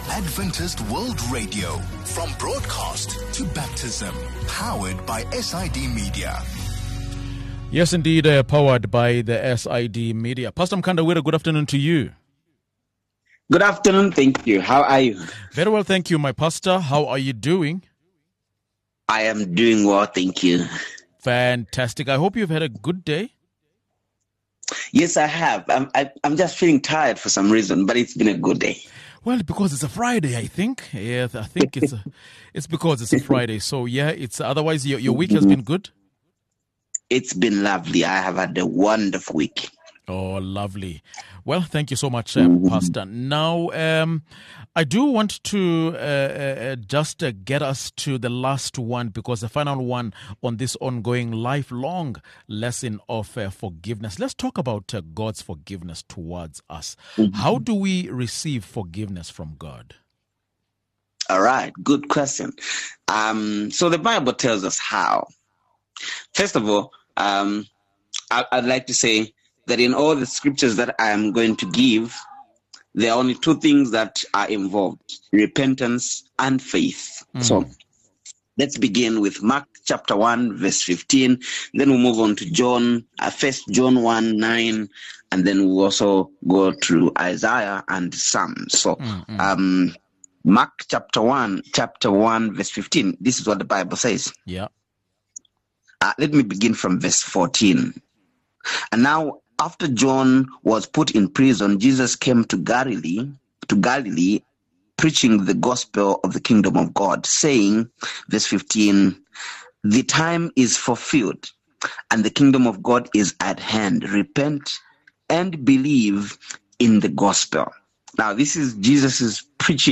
This conversation will be the final one in this on-going life- long lesson on Forgiveness.